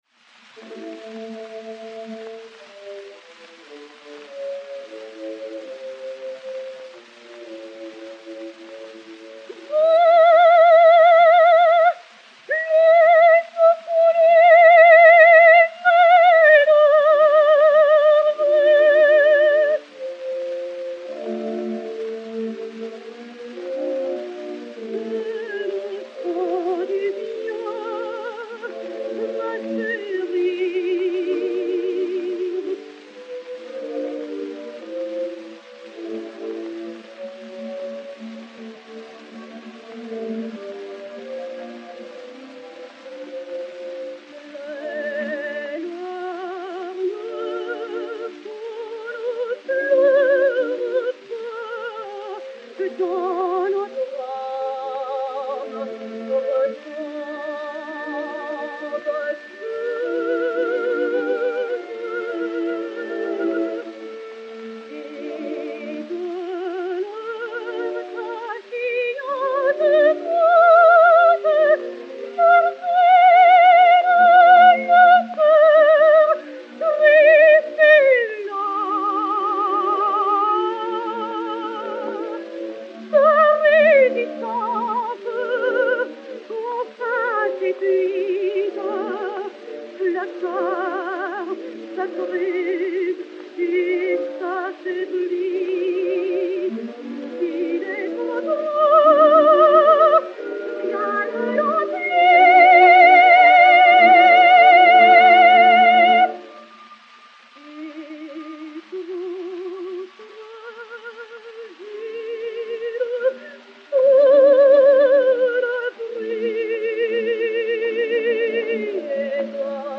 Marthe Chenal (Charlotte) et Orchestre dir François Rühlmann